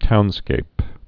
(tounskāp)